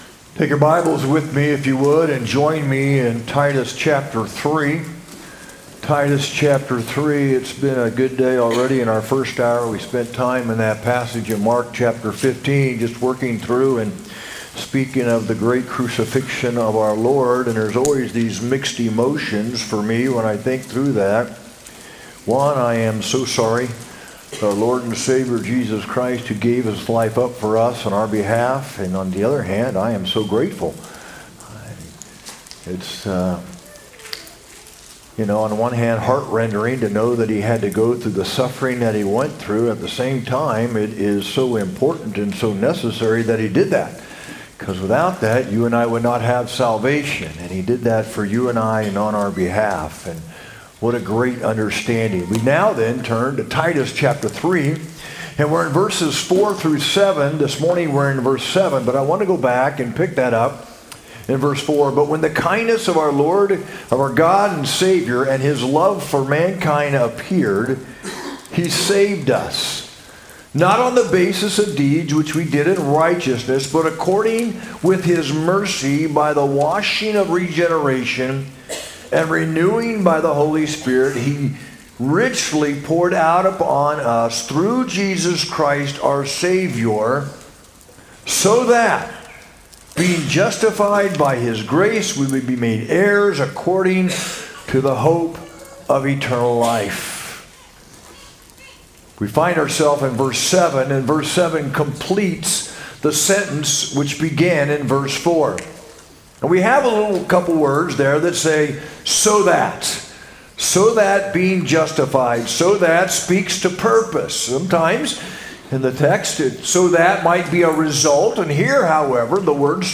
sermon-9-14-25.mp3